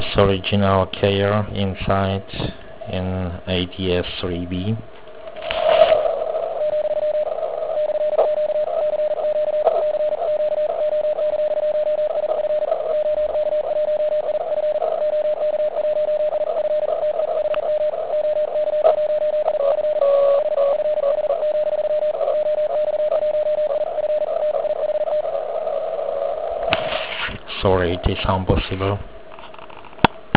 Nedokážu si to vysvětlit čím to je, ale příposlech zkracuje mezery a vzniká efekt jakoby zakmitávání pádla.
Tedy to co slyšíte, je z příposlechu.